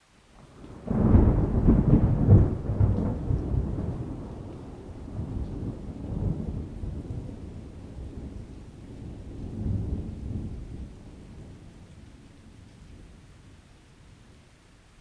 thunder-1.ogg